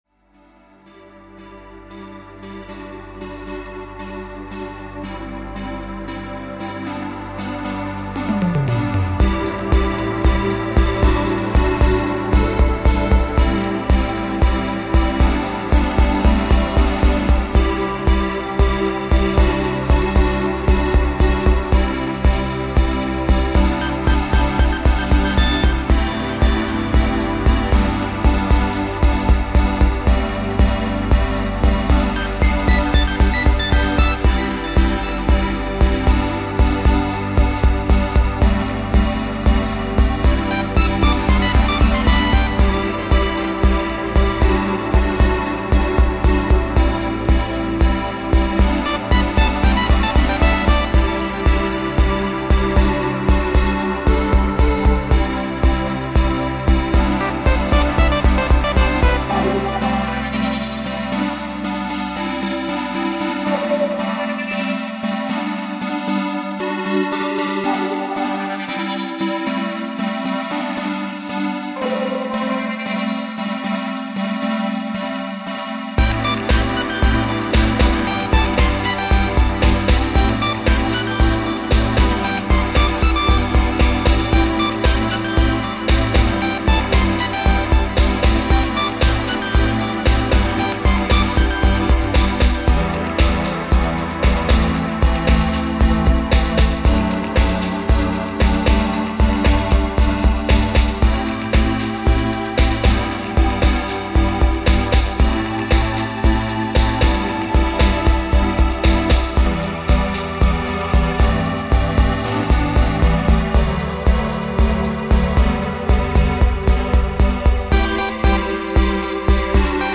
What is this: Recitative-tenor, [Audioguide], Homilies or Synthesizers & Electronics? Synthesizers & Electronics